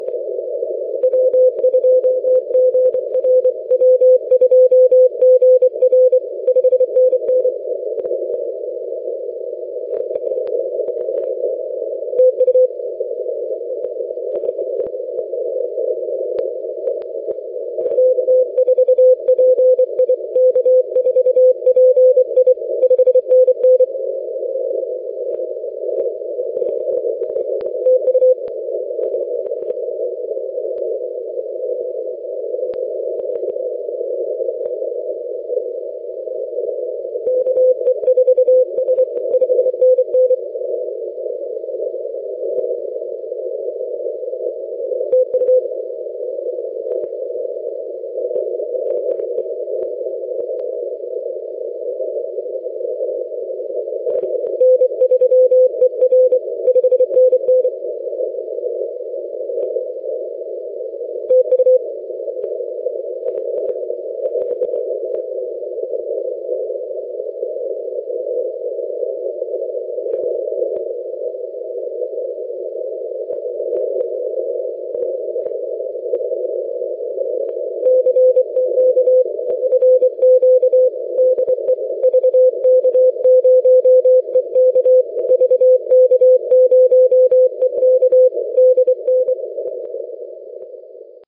VK0EK DXpedition to Heard Island as copied at my station on 80 meters.
Compare how much stronger the signal is on this band.